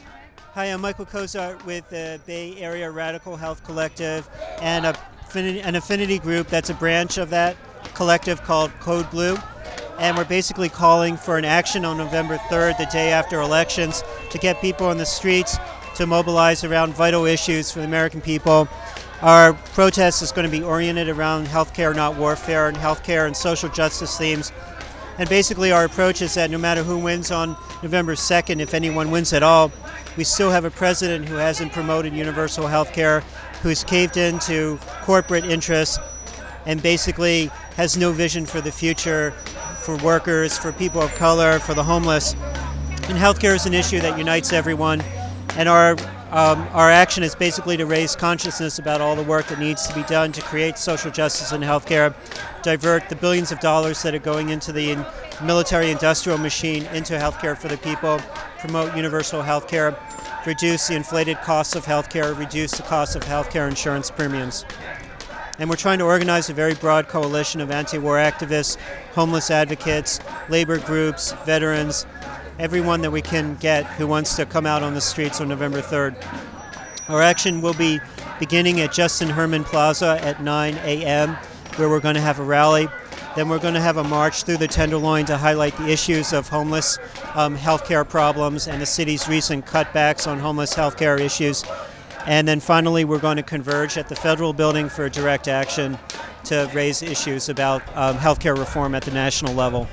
Sounds and Interviews from the Westin St. Francis picket line after the UNITE/HERE Local 2 rally in Union Square